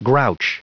Prononciation du mot grouch en anglais (fichier audio)
Prononciation du mot : grouch